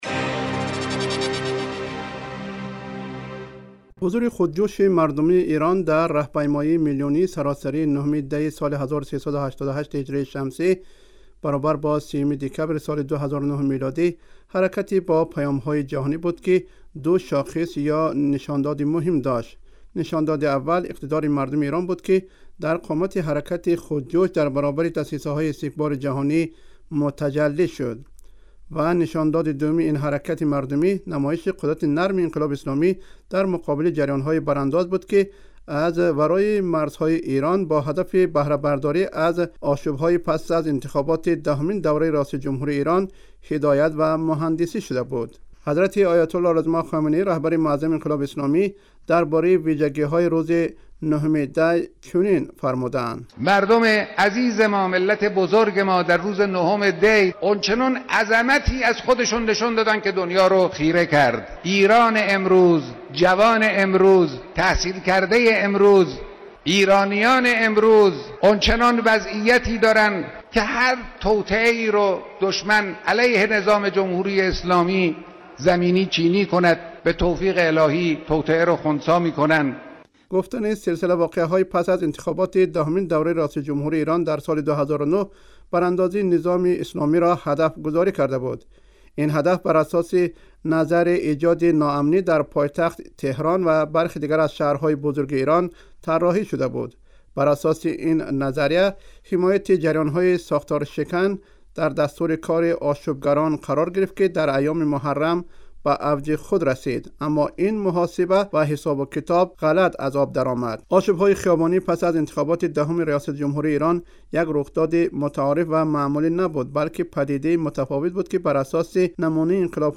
گزارش ویژه: نگاهی به حماسه روز 9 دی مردم ایران